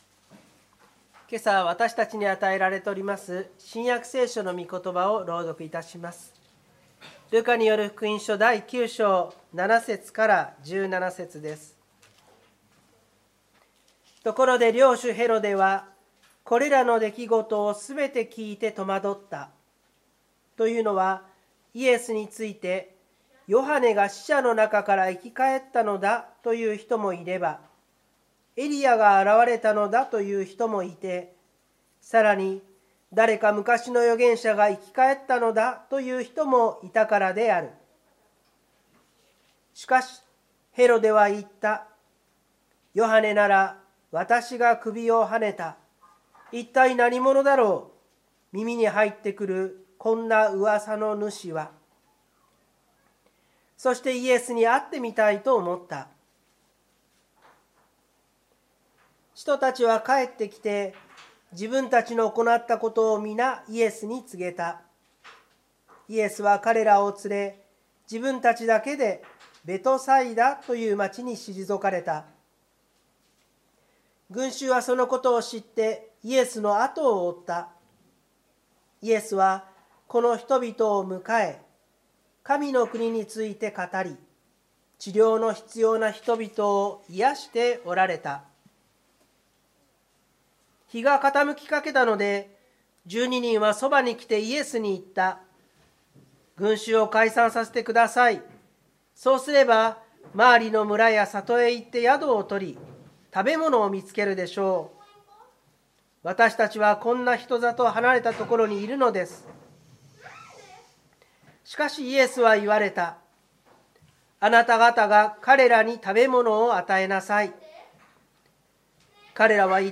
湖北台教会の礼拝説教アーカイブ。
日曜朝の礼拝